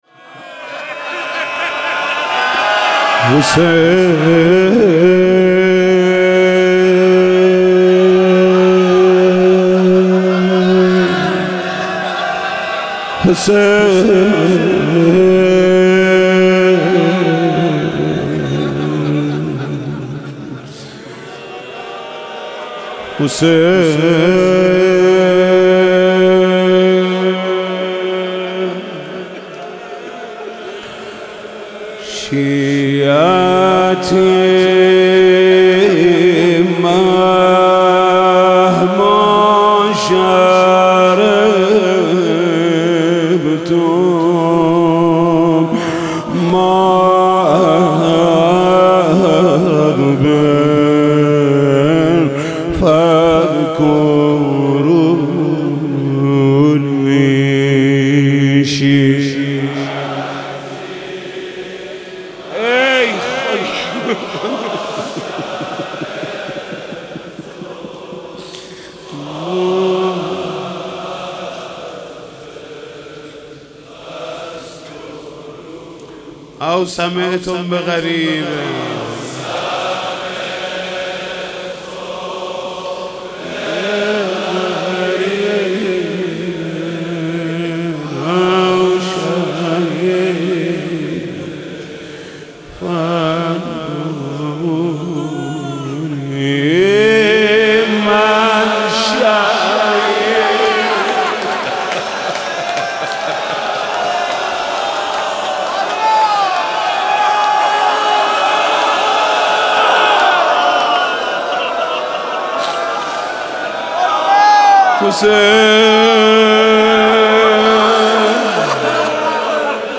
بشنوید | مداحی روز اول محمود کریمی محرم 99 | نیوزین